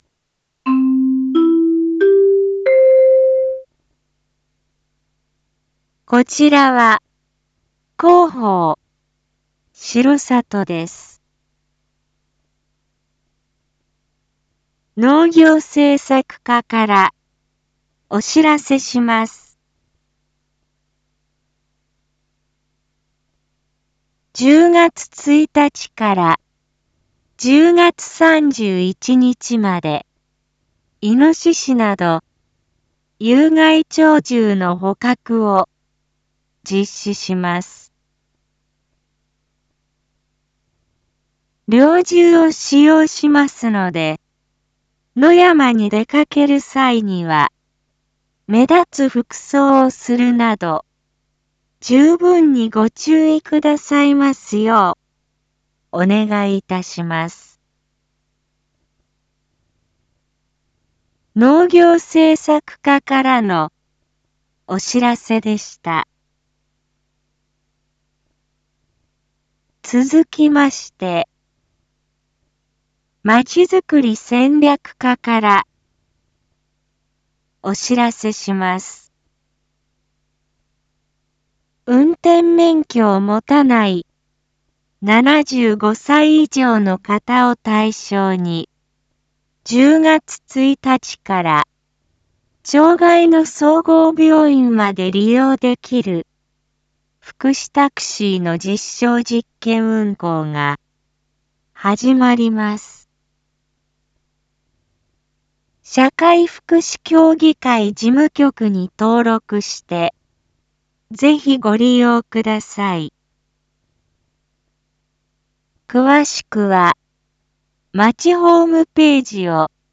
一般放送情報
Back Home 一般放送情報 音声放送 再生 一般放送情報 登録日時：2024-09-30 19:02:33 タイトル：②有害鳥獣捕獲について インフォメーション：こちらは、広報しろさとです。